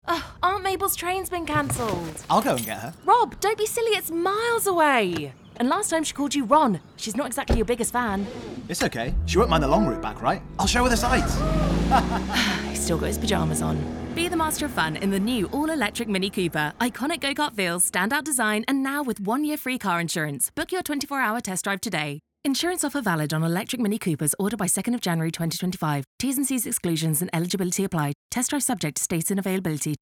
Male
I work in both northern British and neutral British accents with a charming, warm, confident, trustworthy, and friendly voice.
A National Radio Ad For Mini
0409Mini_BMW_-_Mini_Family_Ad.mp3